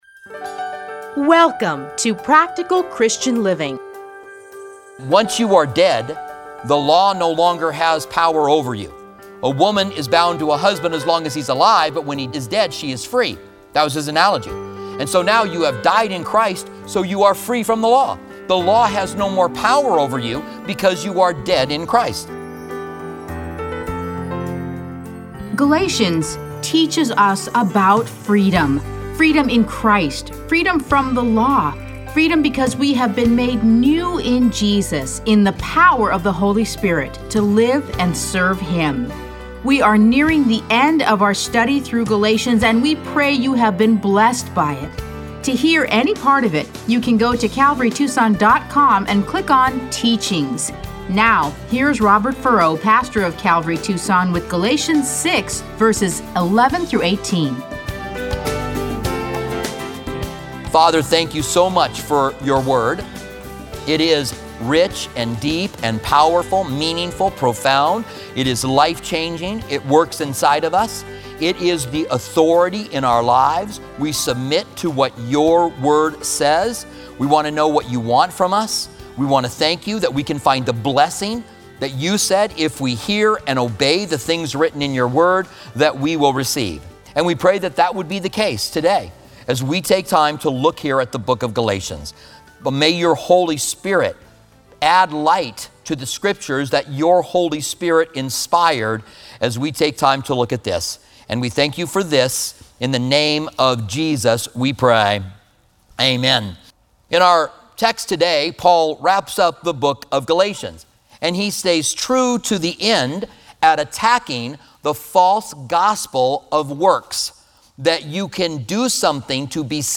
Listen to a teaching from Galatians 6:11-18.